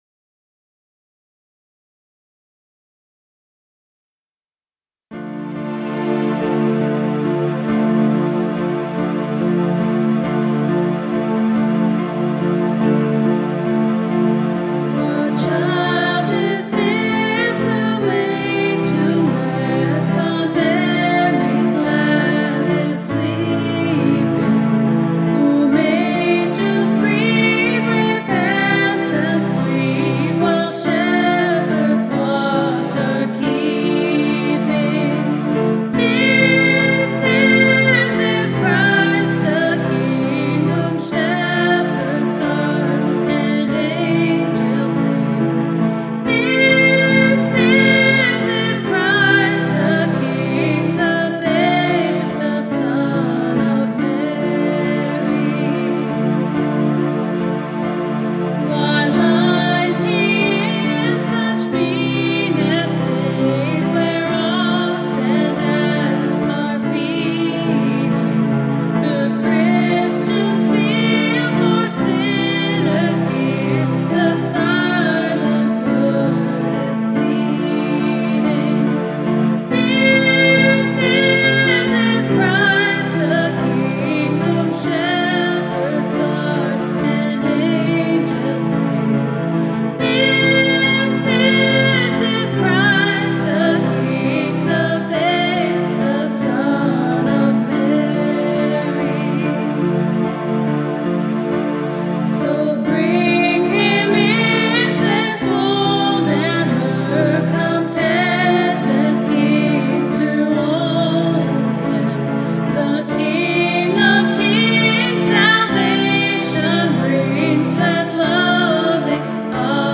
LISTEN TO THE SOME CHRISTMAS FAVOURITES RECORDED BY OUR WORSHIP TEAM!